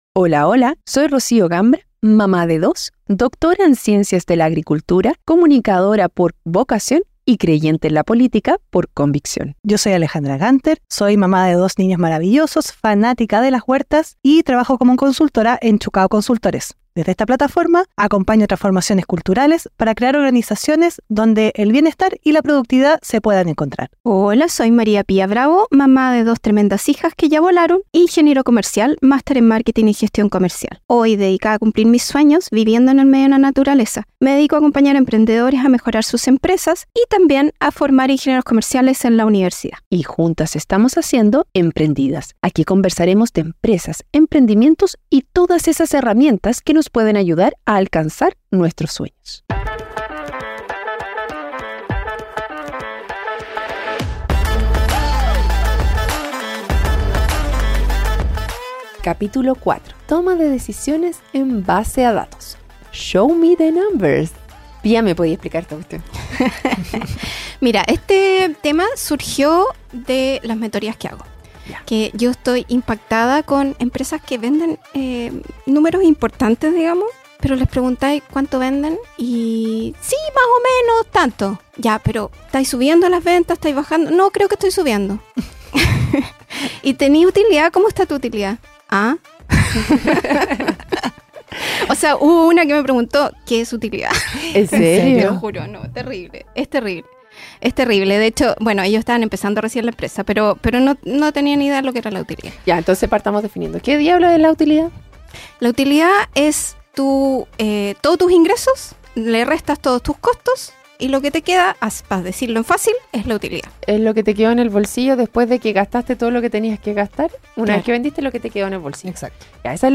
🔵Somos tres amigas